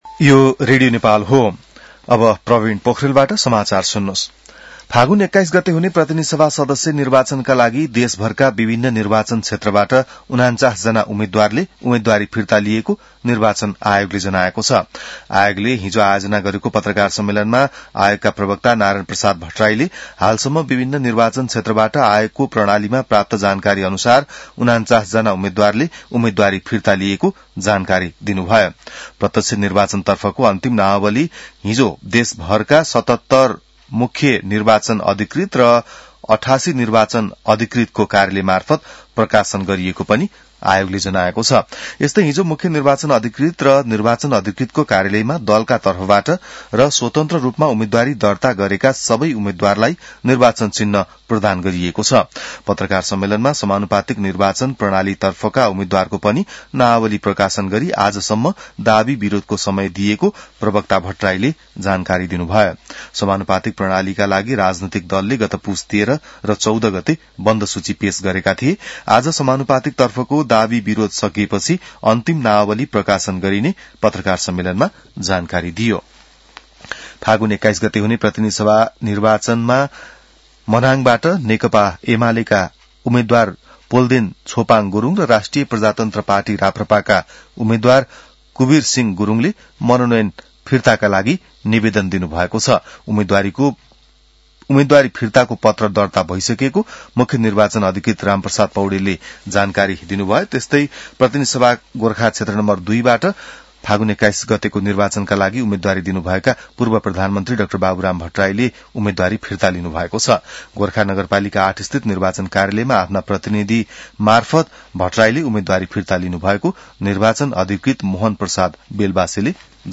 बिहान ६ बजेको नेपाली समाचार : १० माघ , २०८२